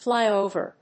アクセント・音節flý・òver